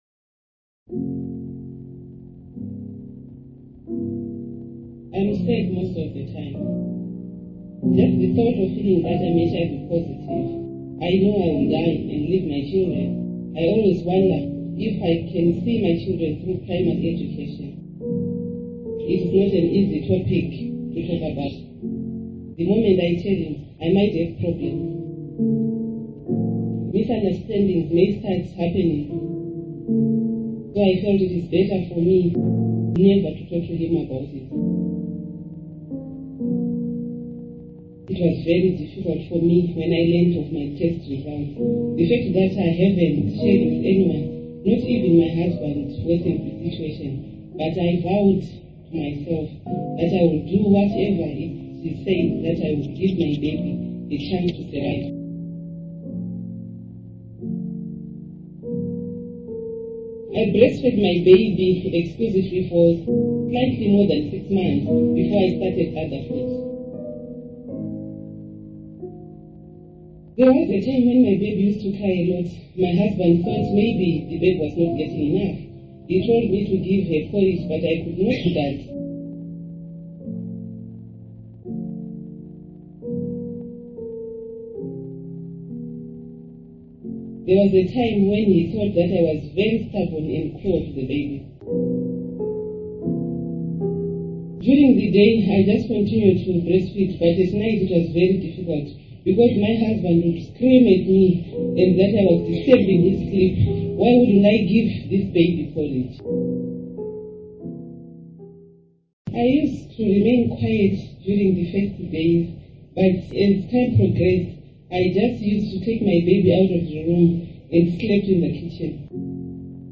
This is a transcript from an interview with an HIV+ mother.